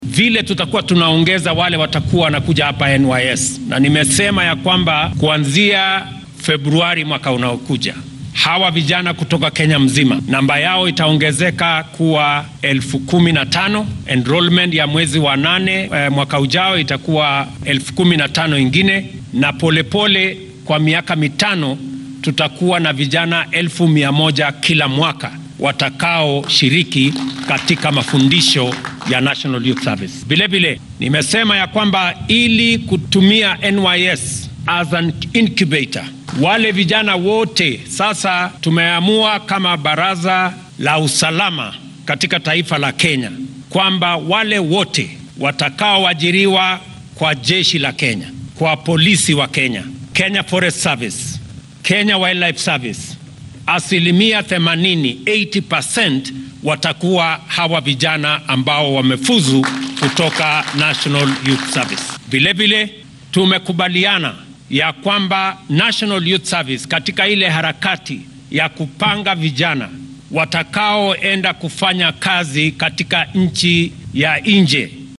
Madaxweynaha dalka William Ruto ayaa maanta guddoomiyay xaflad ka dhacday magaalada Gilgil ee ismaamulka Nakuru oo ay ku qalin jabiyeen saraakiil ku biiraya adeegga qaran ee dhallinyarada ee NYS.